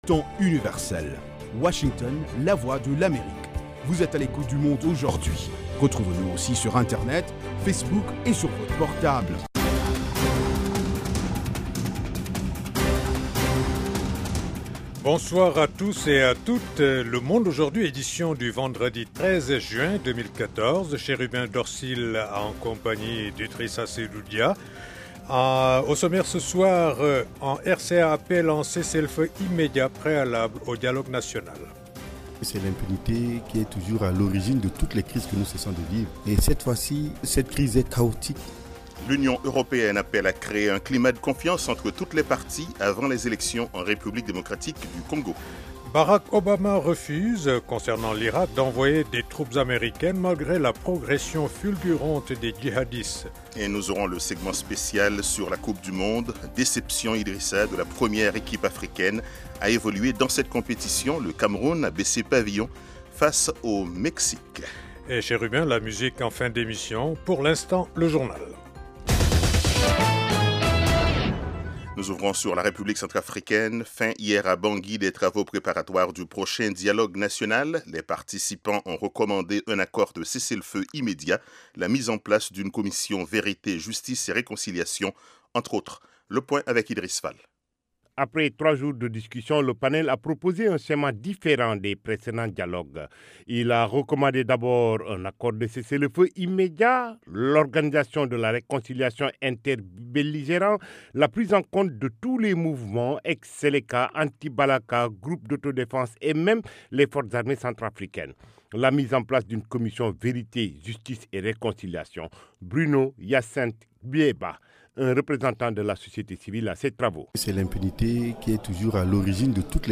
Toute l’actualité sous-régionale sous la forme de reportages et d’interviews. Des dossiers sur l'Afrique etle reste du monde. Le Monde aujourd'hui, édition pour l'Afrique de l’Ouest, c'est aussi la parole aux auditeurs pour commenter à chaud les sujets qui leur tiennent à coeur.